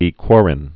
(ē-kwôrĭn, ē-kwŏr-)